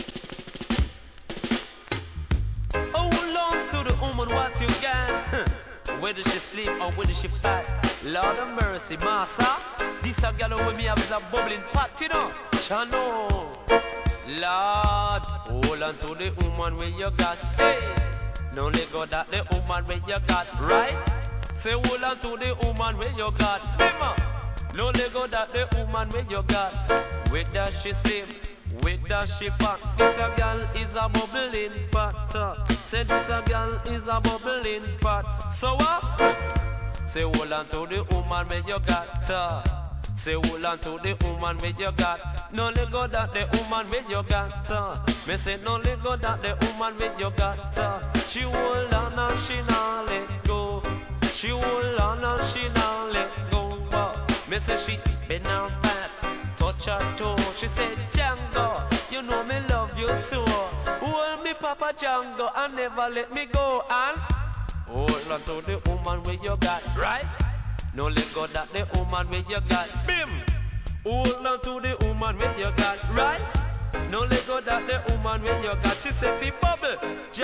REGGAE
NICE DeeJay STYLE!!!